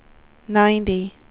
WindowsXP / enduser / speech / tts / prompts / voices / sw / pcm8k / number_110.wav
number_110.wav